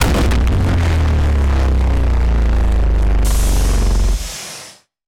laserbig.ogg